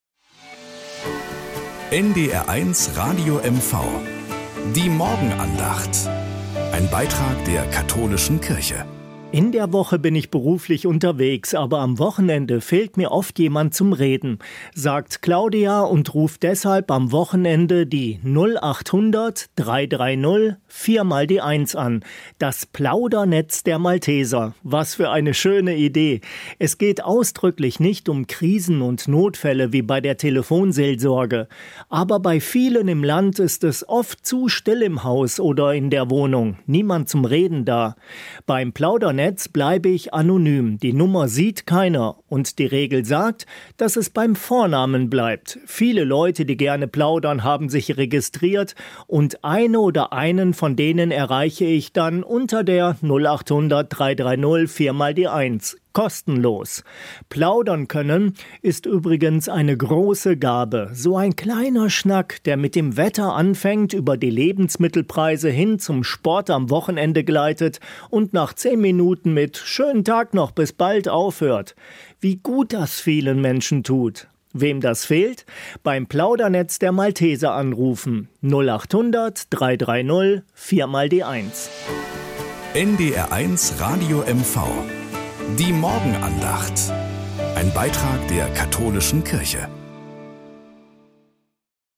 Morgenandacht bei NDR 1 Radio MV
Um 6:20 Uhr gibt es in der Sendung "Der Frühstücksclub" eine
Evangelische und katholische Kirche wechseln sich